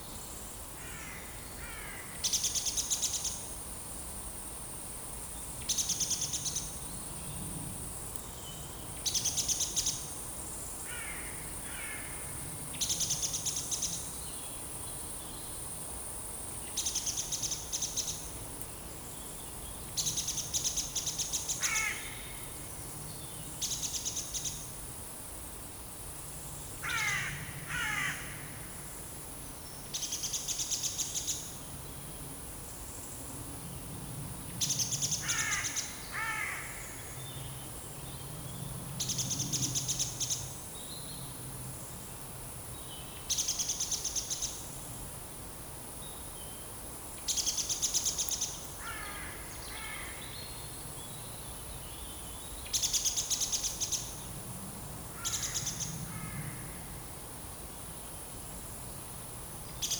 Monitor PAM
Turdus merula
Corvus corone
Turdus philomelos